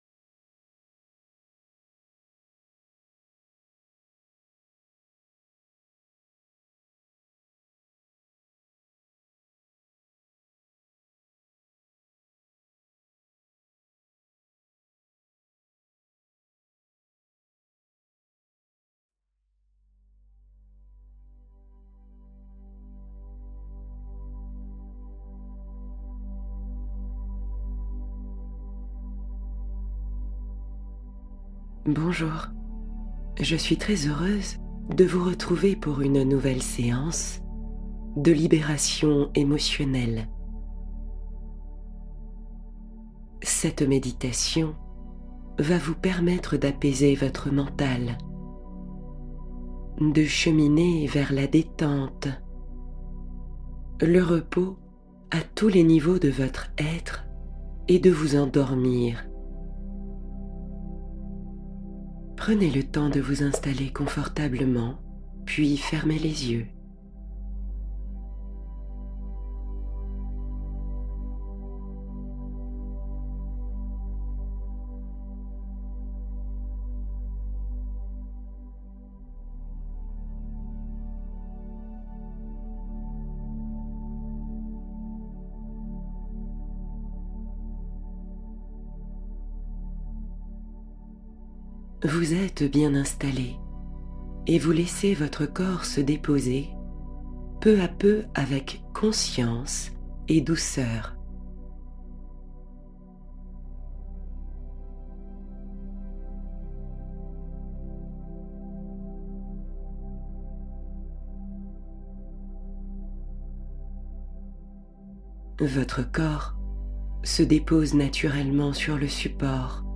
Rencontre avec soi-même — Méditation guidée de reconnexion intérieure